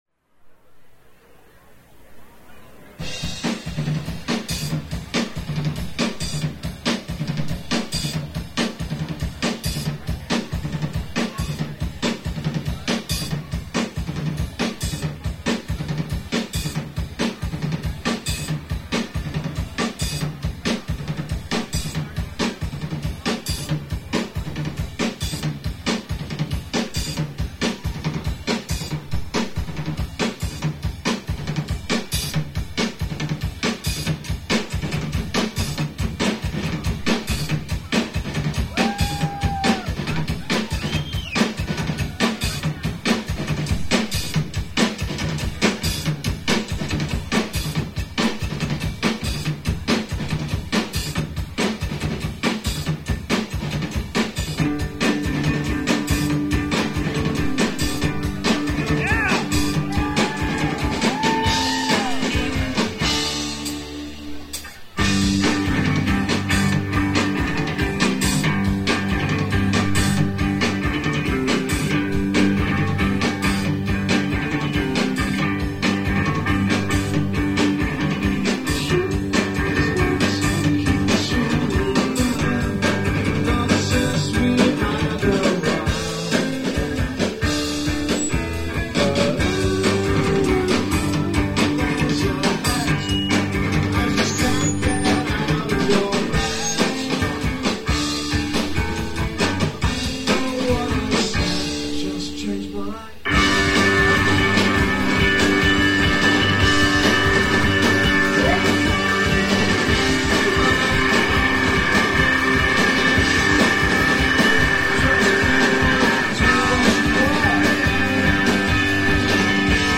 The Fine Line Cafe
(Live)